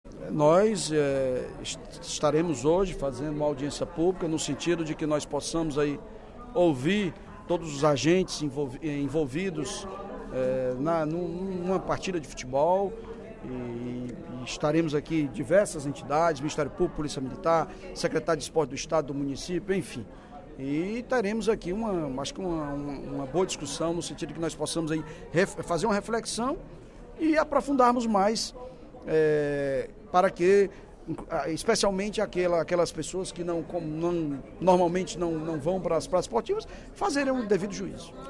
O deputado Evandro Leitão (PDT) defendeu, durante o primeiro expediente da sessão plenária desta quarta-feira (24/05), a regulamentação da venda de bebidas alcoólicas nas praças esportivas.